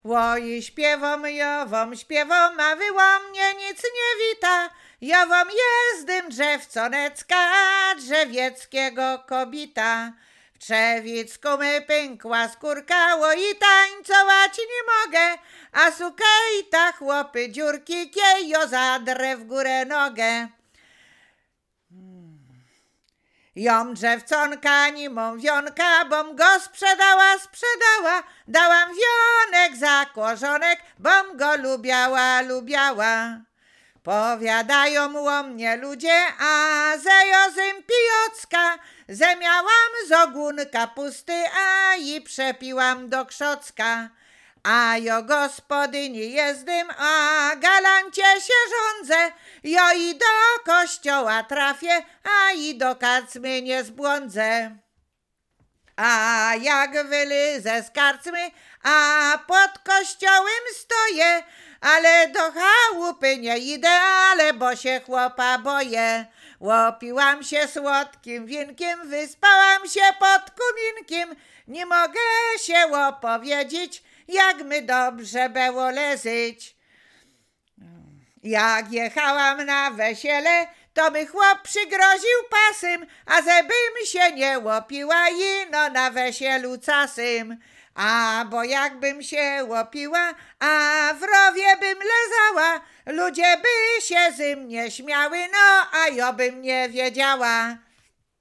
Łowickie
Przyśpiewki
miłosne żartobliwe przyśpiewki